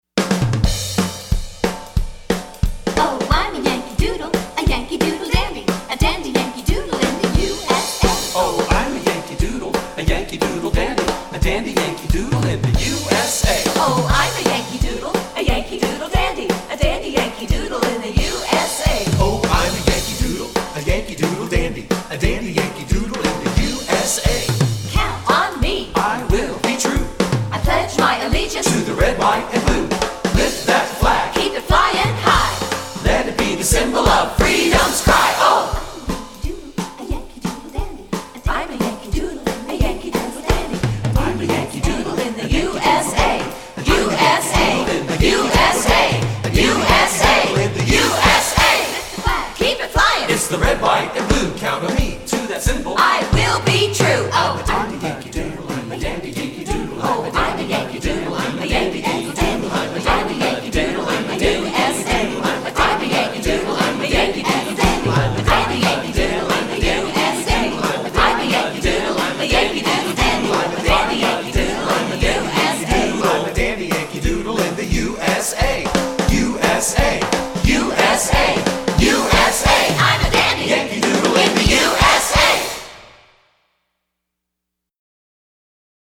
Voicing: 4-Part